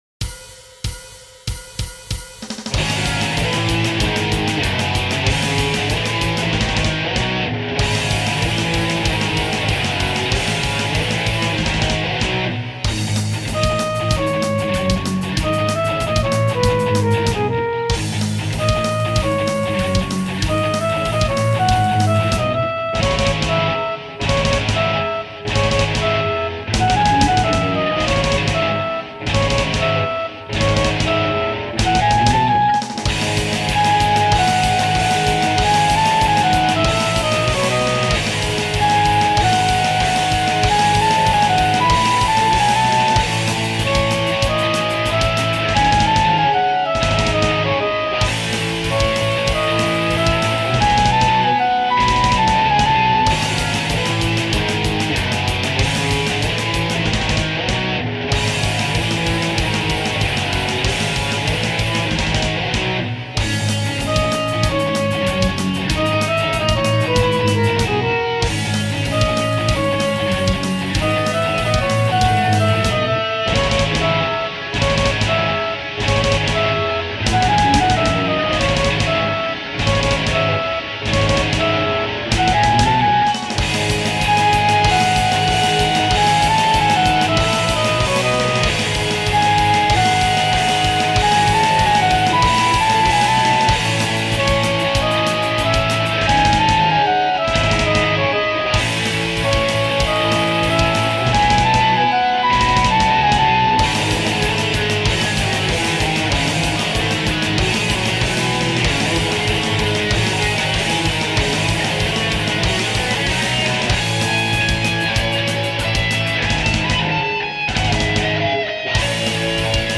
■■デモ音源■■